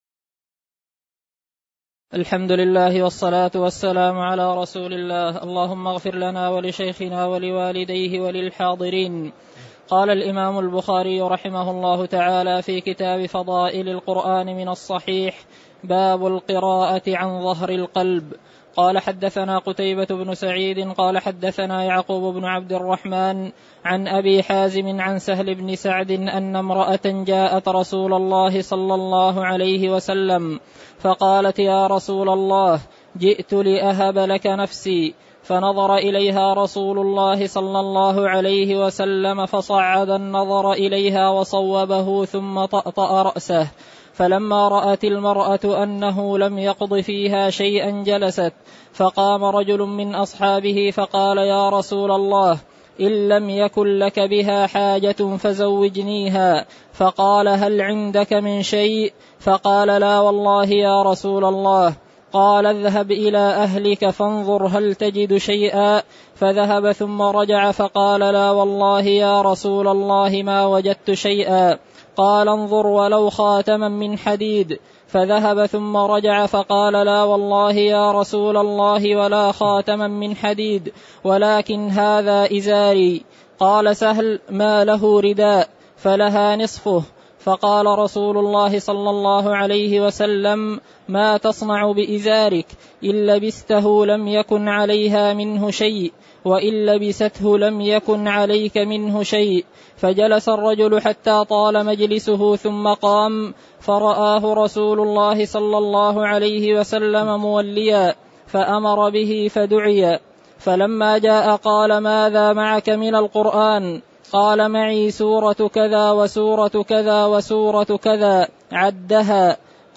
تاريخ النشر ١٩ رمضان ١٤٣٩ هـ المكان: المسجد النبوي الشيخ